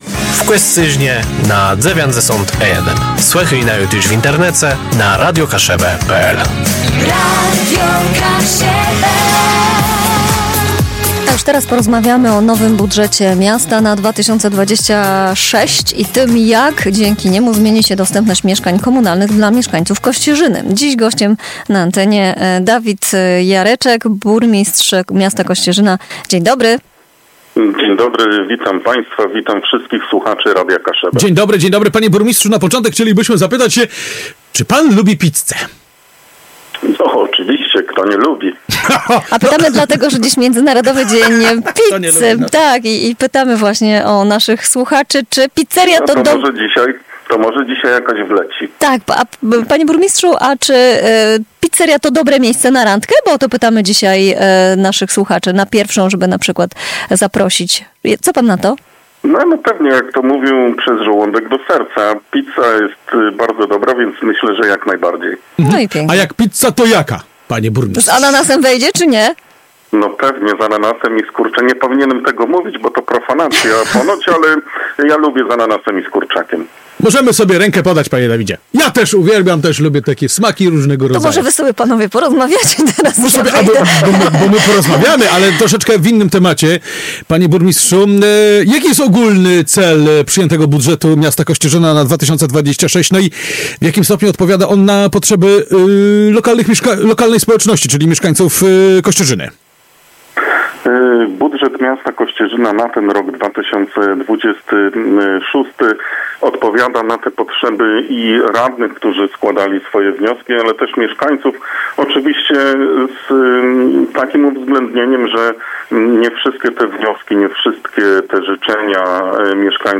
O ambitnych planach inwestycyjnych, budowie nowych lokali komunalnych oraz o tym, dlaczego warto zamieszkać w sercu Kaszub, rozmawialiśmy z burmistrzem miasta Kościerzyna, Dawidem Jereczkiem.
rozmowa_DJereczek.mp3